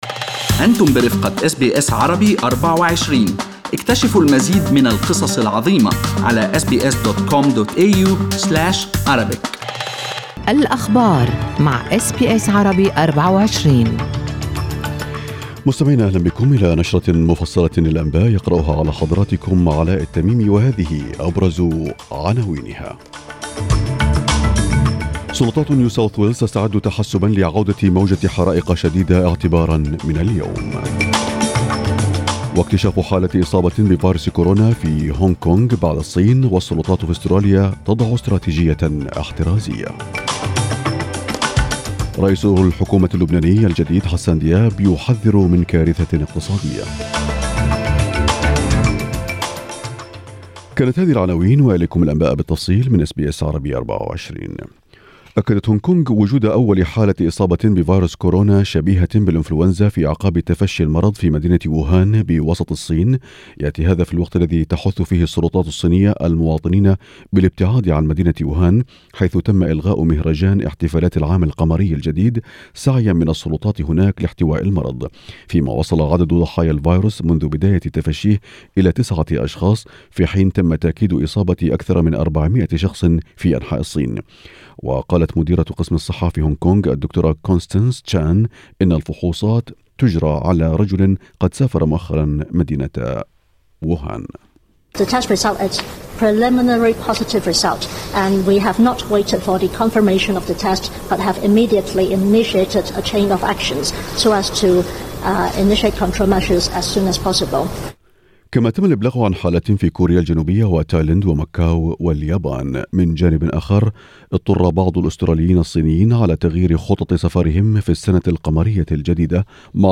نشرة أخبار الصباح 23/01/2020
Arabic News Bulletin Source: SBS Arabic24